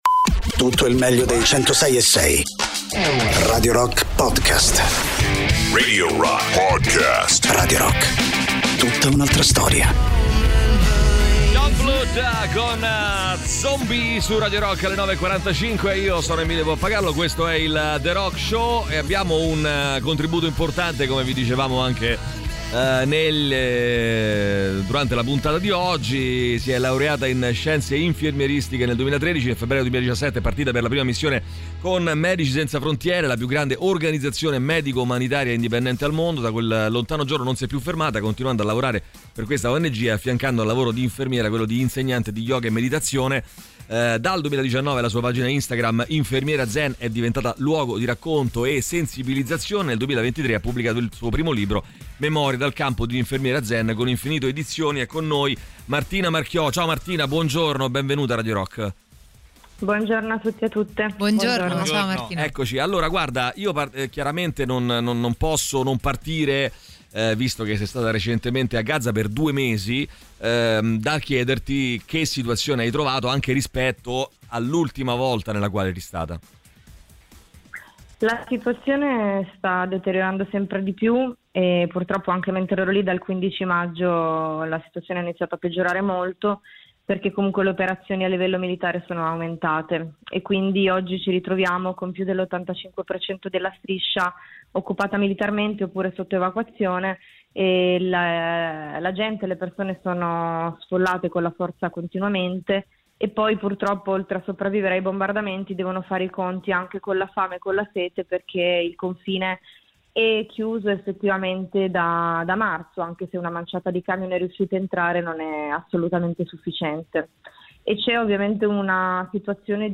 Interviste
ospite telefonico